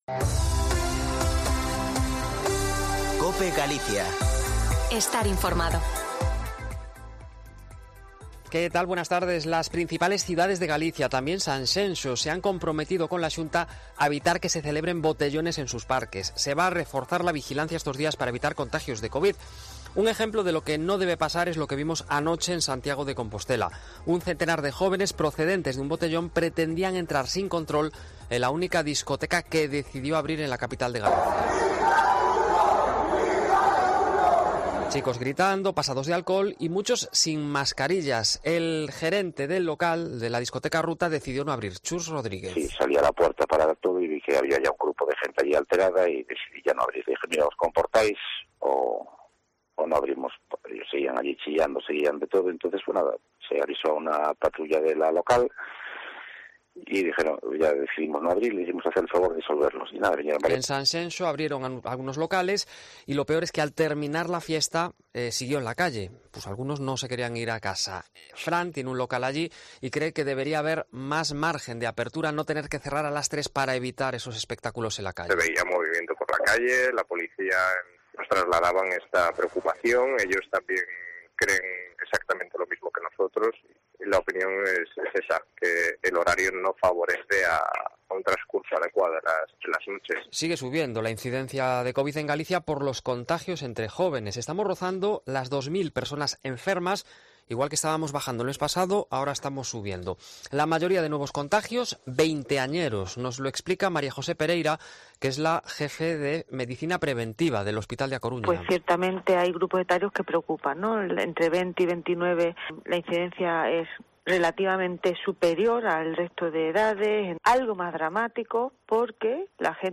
Informativo Mediodia en Cope Galicia 02/07/2021. De 14.48 a 14.58h